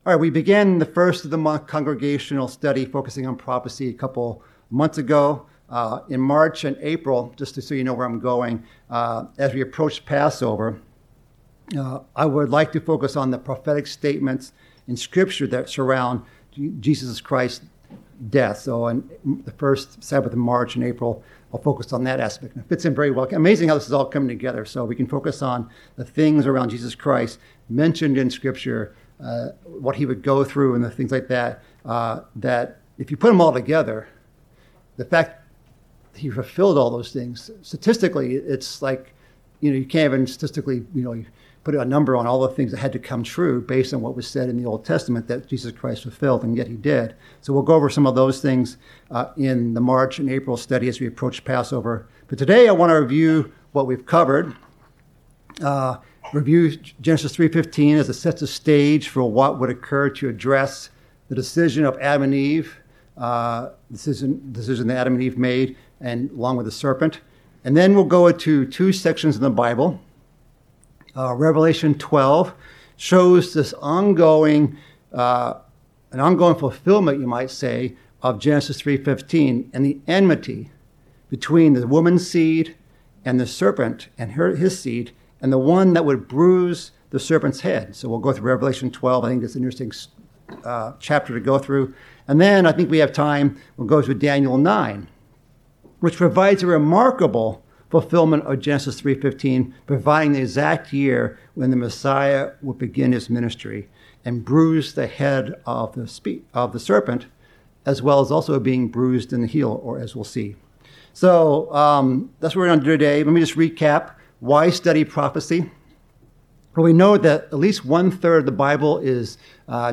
Sermons
Given in Chicago, IL Northwest Indiana